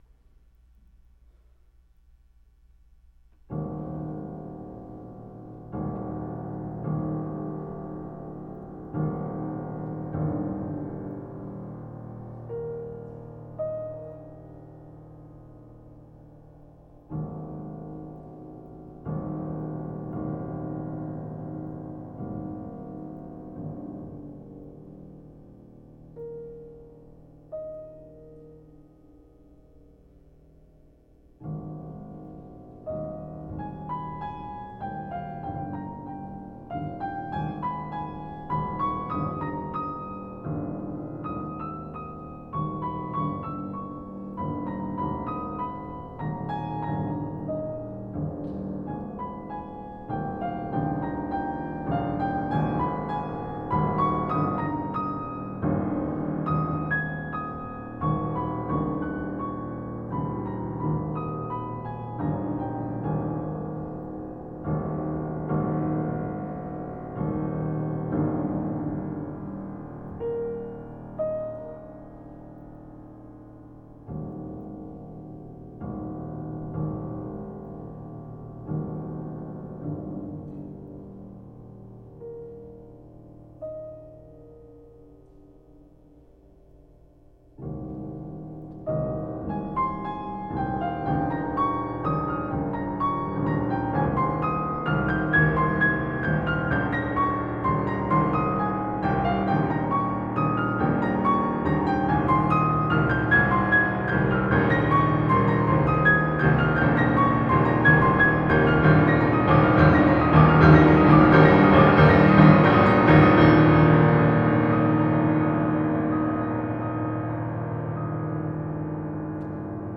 I thought I had lost the printed program from that event but I finally found it, and it appears below, along with a number of audio excerpts from the concert.